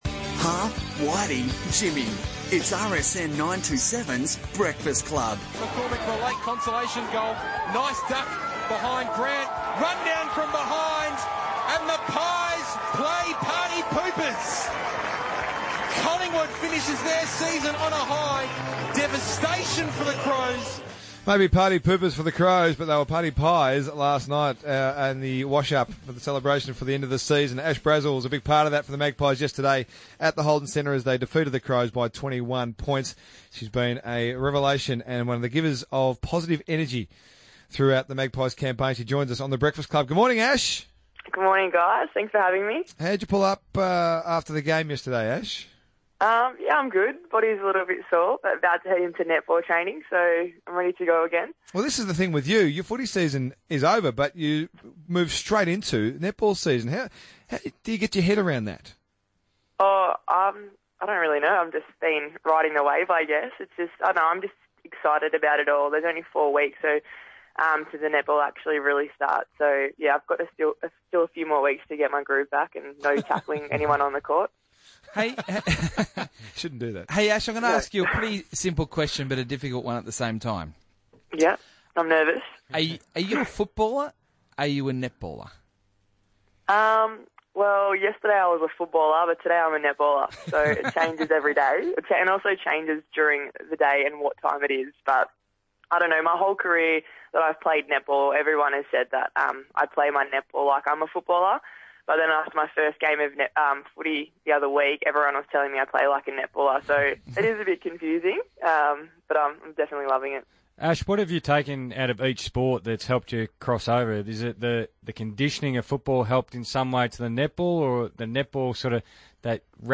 Radio: Ash Brazill on RSN
Listen in as dual-sport Magpie Ash Brazill catches up with the RSN Breakfast team after Collingwood's upset victory over the Adelaide Crows.